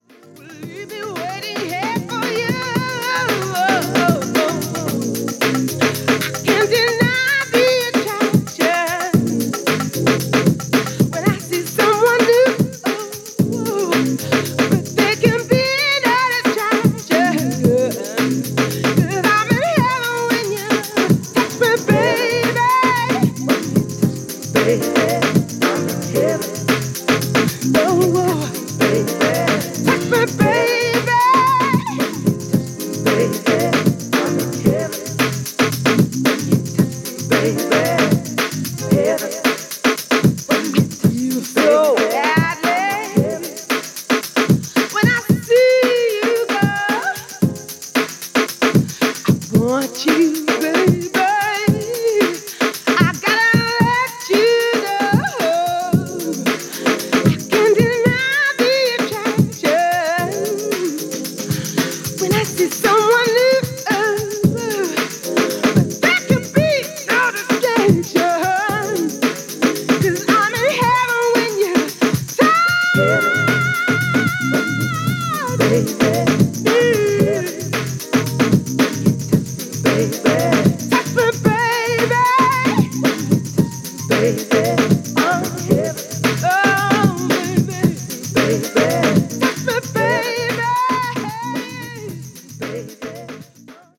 この頃のキックは、本当にかっこいいですね！！！